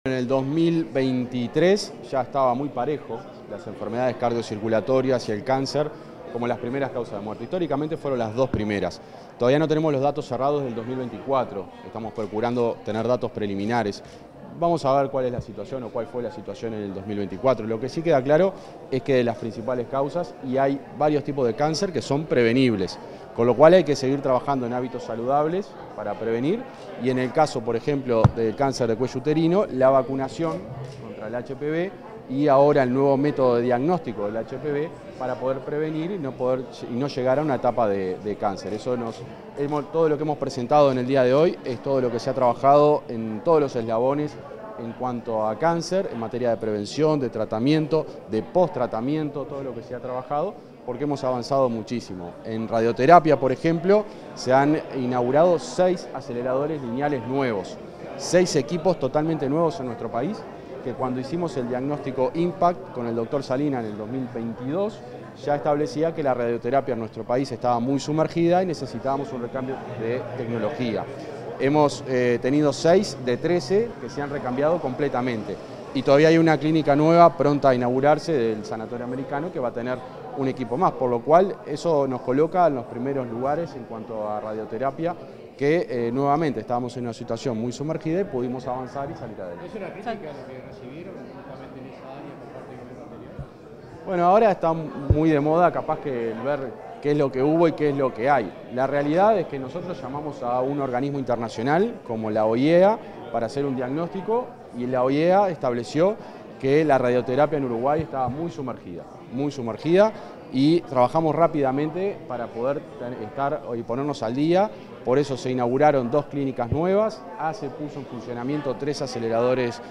Declaraciones del ministro interino de Salud Pública, José Luis Satdjian
Luego de una ceremonia por el Día Mundial contra el Cáncer, este 4 de febrero, el ministro interino de Salud Pública, José Luis Satdjian, dialogó con los medios informativos presentes